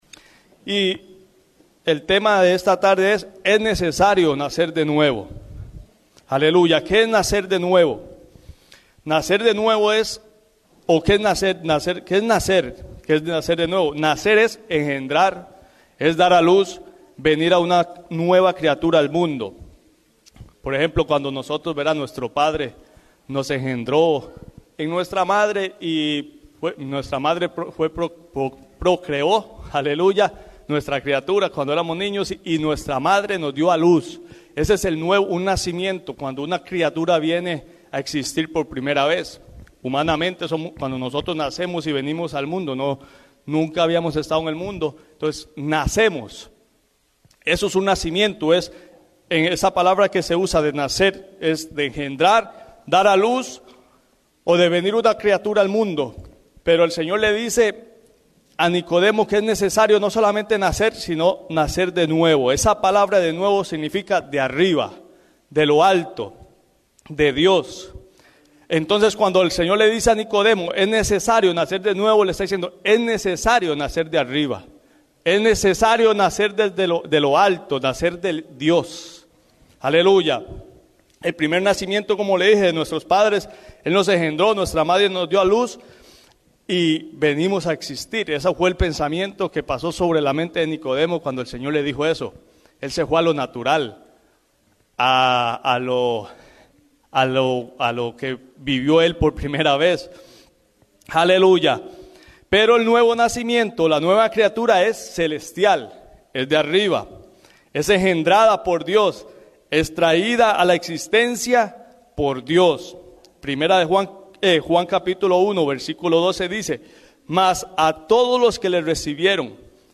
Es Necesario Nacer De Nuevo Predica